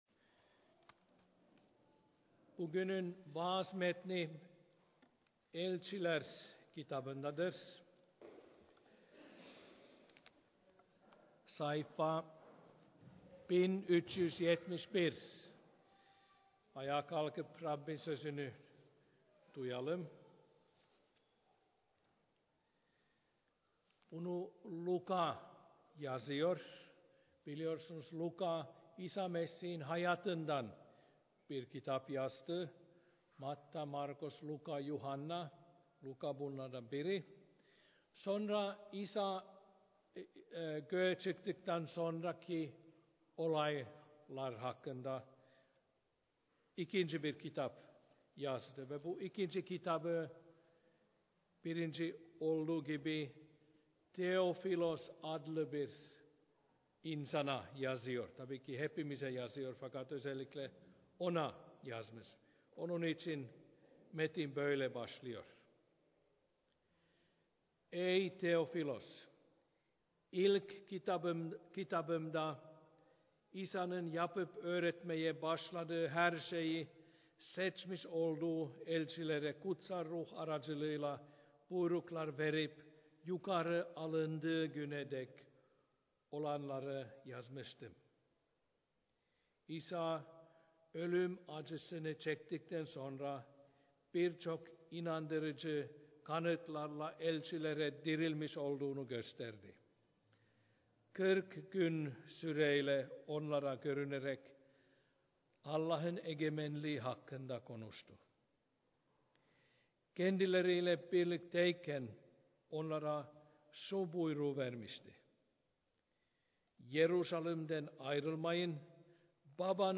2011 yılı: Yeni Antaşma’dan vaazlar
Yeni Antlaşma’nin diğer kitaplarından vaazlar